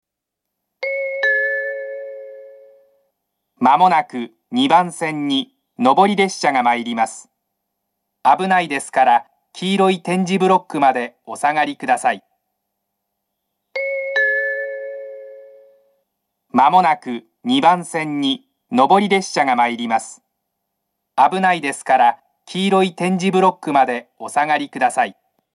２番線上り接近放送 上下本線です。
murayama-2bannsenn-nobori-sekkinn1.mp3